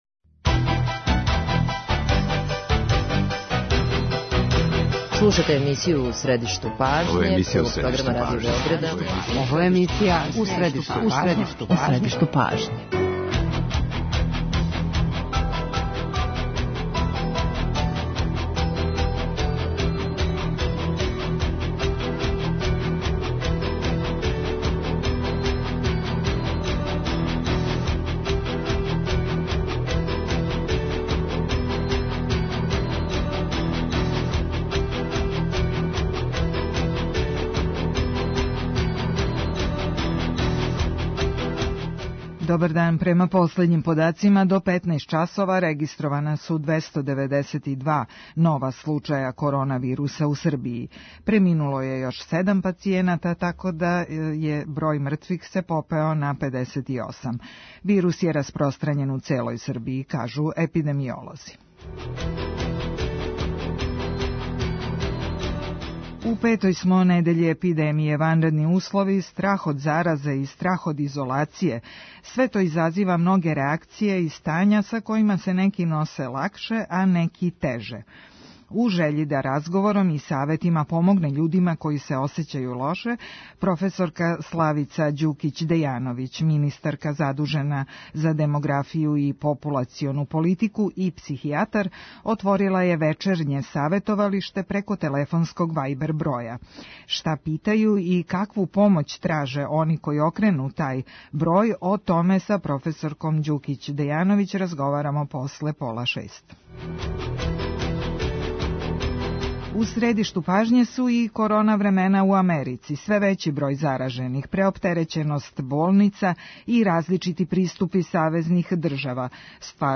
Шта питају и какву помоћ траже они који окрену тај број? Може ли се овладати страхом? Саговорница емисије је проф. Славица Ђукић Дејановић.